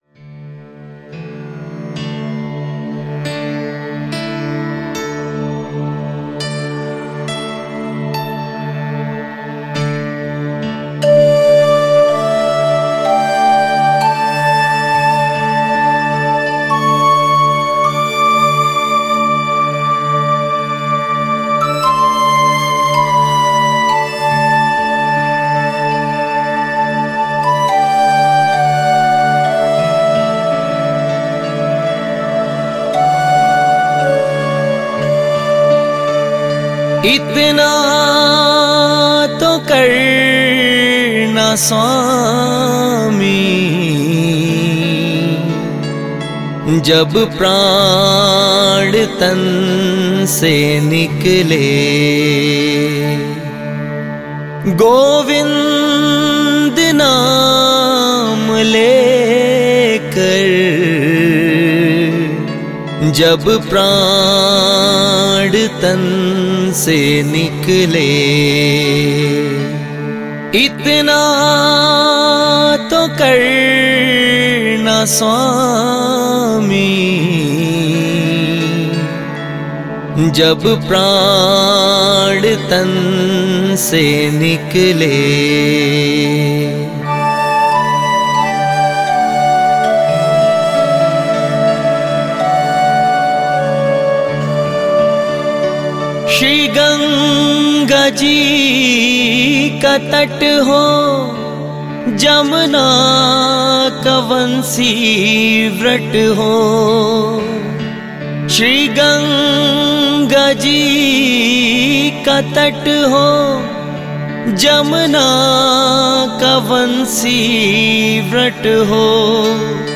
🎵 Itnā To Karnā Svāmī / ઇતના તો કરના સ્વામી – રાગ : દસે